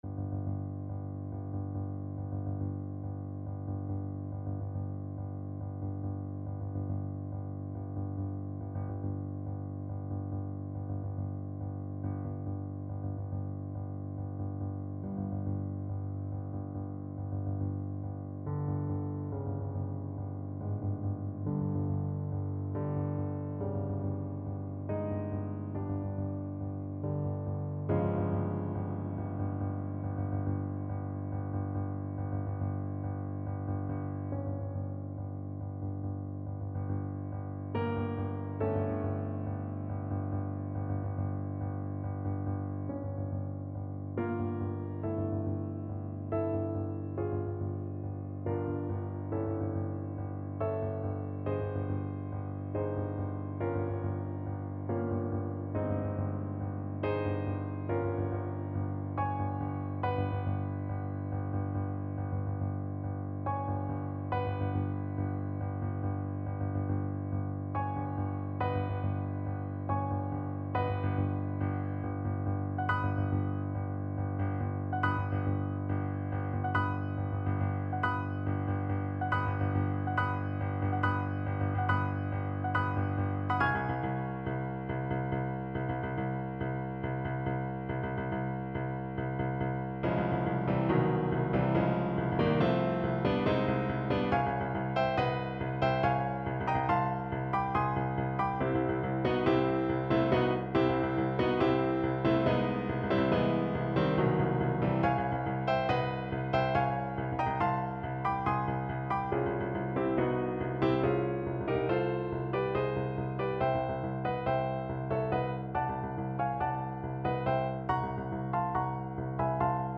Play (or use space bar on your keyboard) Pause Music Playalong - Piano Accompaniment Playalong Band Accompaniment not yet available transpose reset tempo print settings full screen
Allegro = 140 (View more music marked Allegro)
5/4 (View more 5/4 Music)
A minor (Sounding Pitch) B minor (Trumpet in Bb) (View more A minor Music for Trumpet )